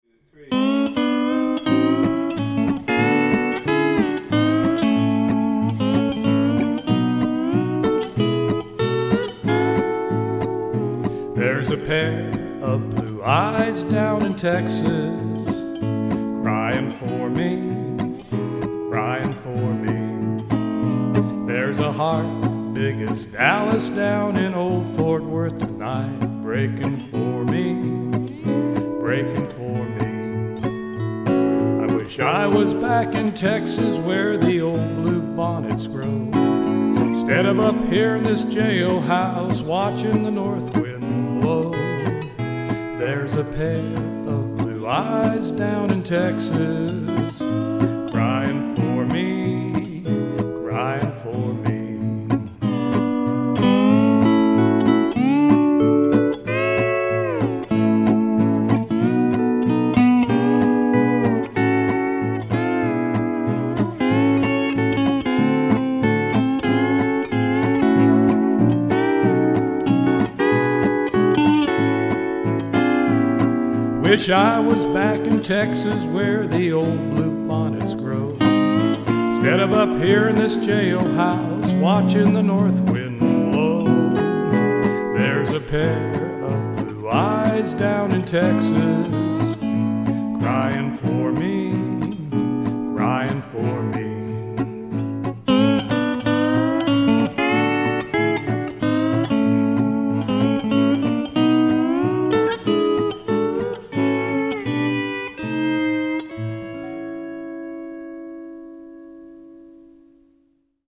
I wanted to get close to the E9th timbre on country songs.